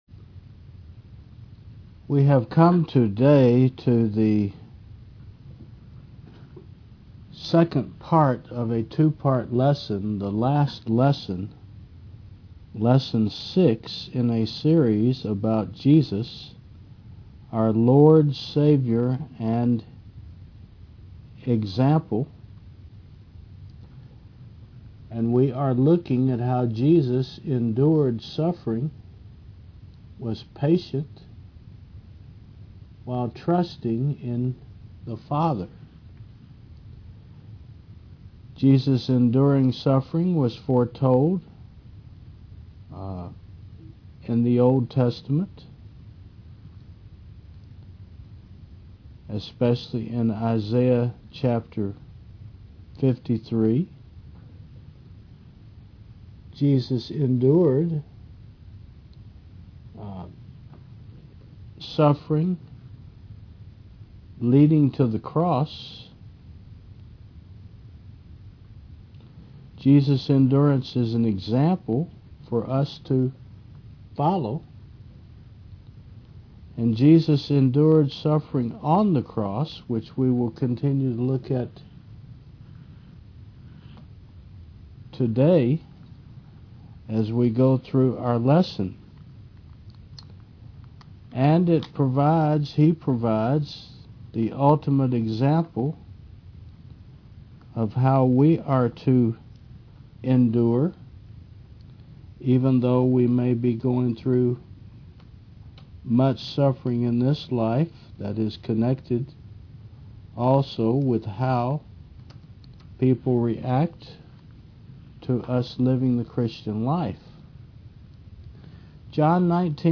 Service Type: Fri. 10 AM